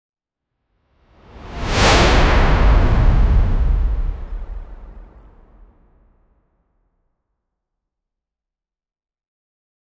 make-a-bold-cinematic-roa-buivk5g3.wav